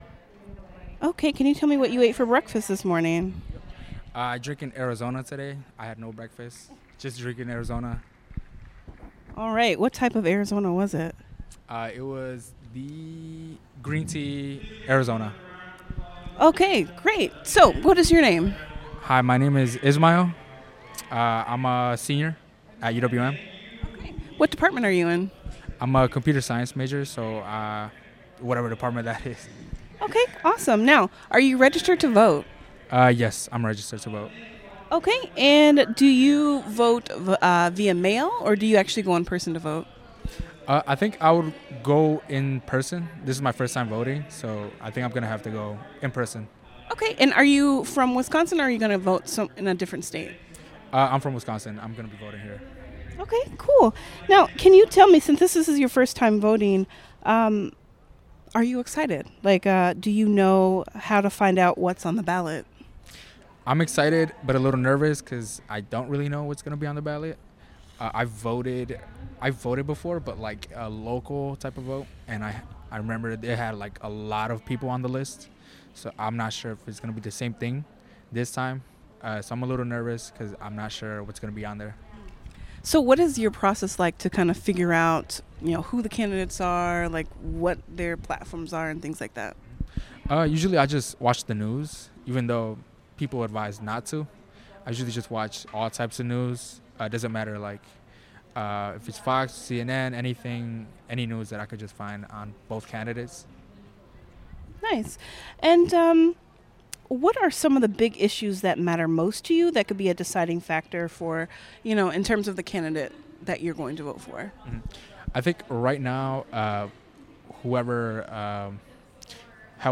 University of Wisconsin-Milwaukee